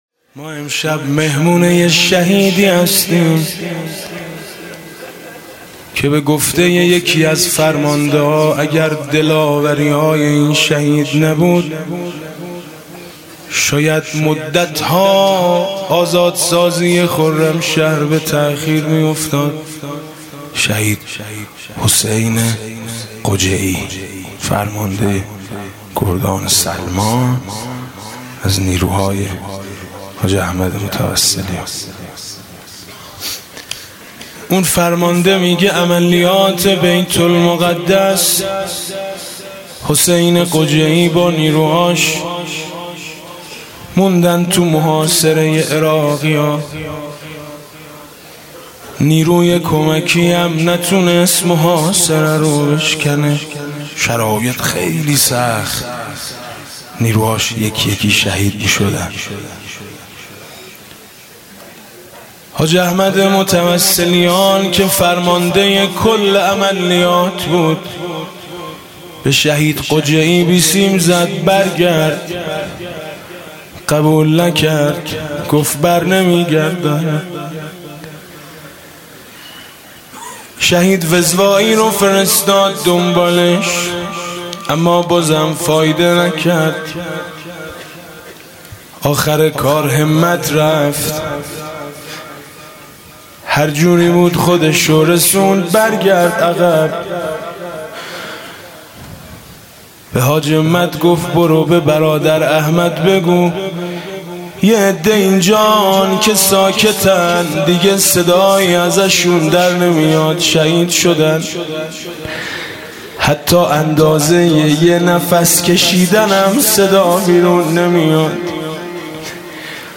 مجموعه کامل صوت مداحی حاج میثم مطیعی در شب اول محرم از سال های 86 تا 97